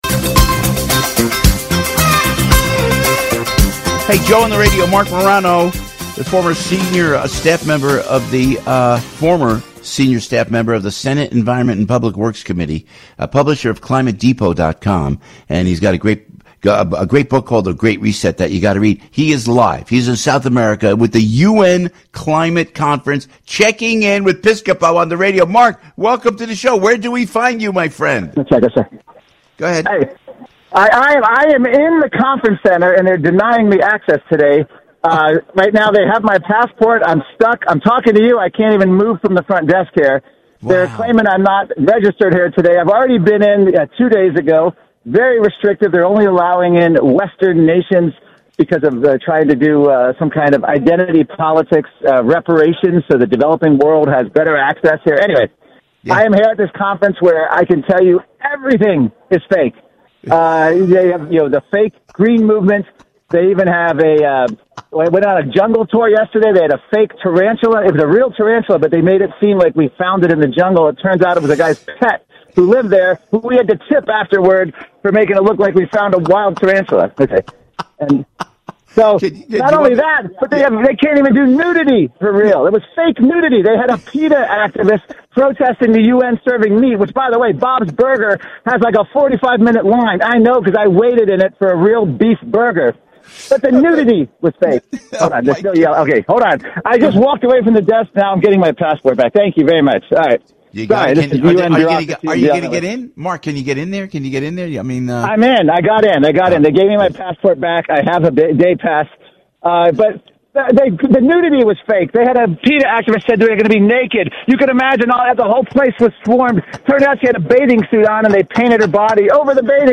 Broadcast Live Nov 12, 2025 Listen